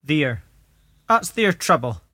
[hawd: hawd, mUHtal]